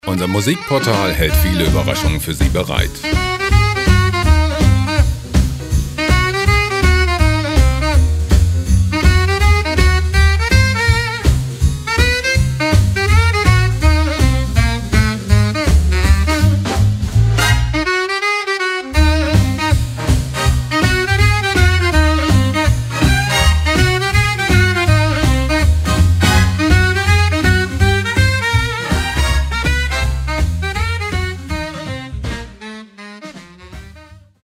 • Big Band Swing